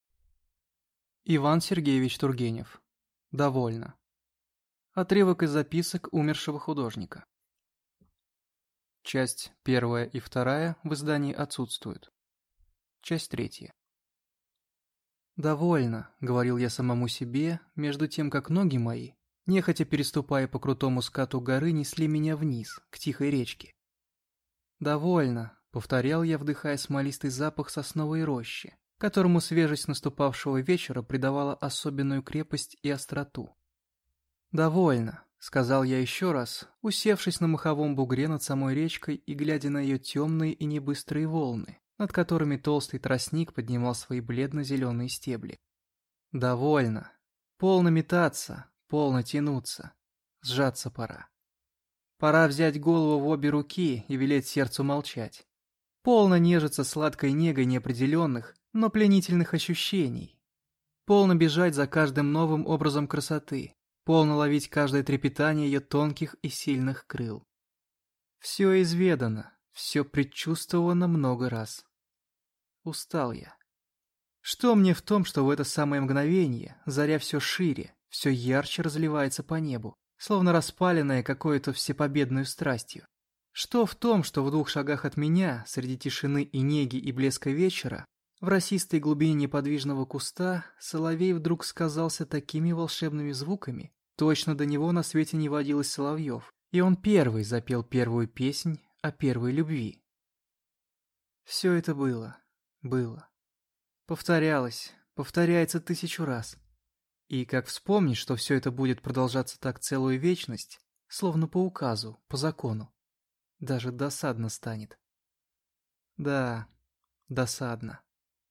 Аудиокнига Довольно | Библиотека аудиокниг
Прослушать и бесплатно скачать фрагмент аудиокниги